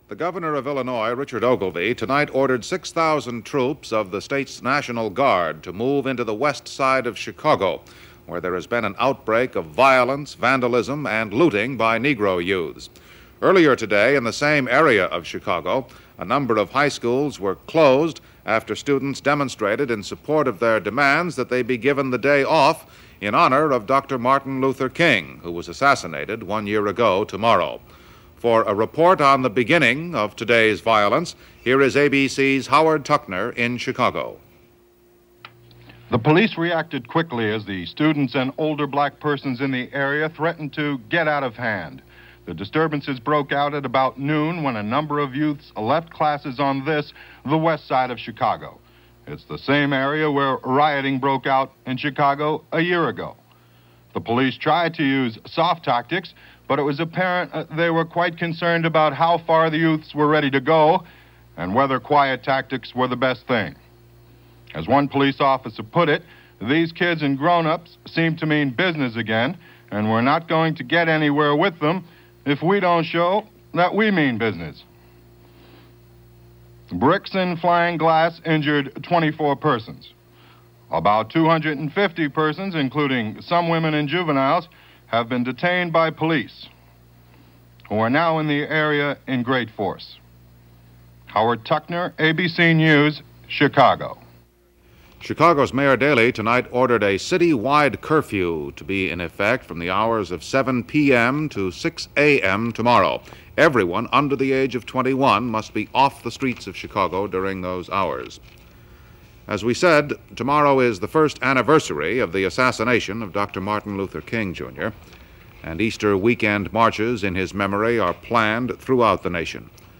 April 3, 1969 – ABC News – Gordon Skene Sound Collection –